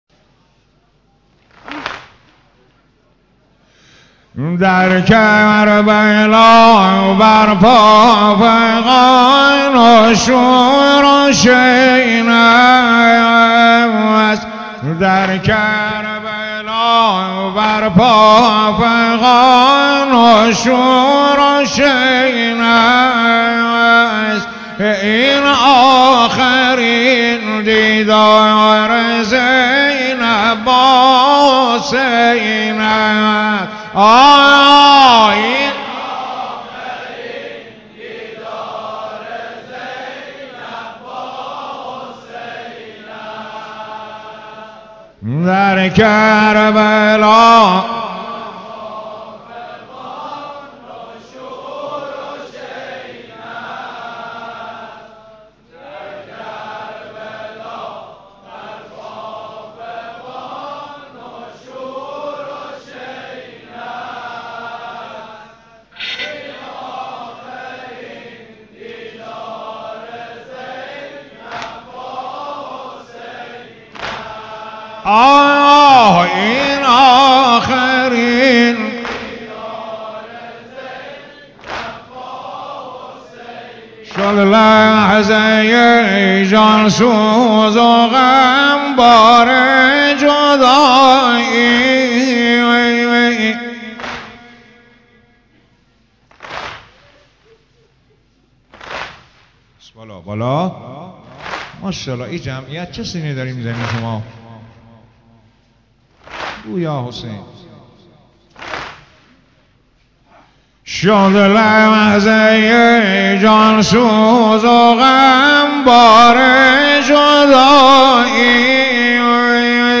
محرم-نوحه-در-کربلا-برپا-فغان...-گراشی.mp3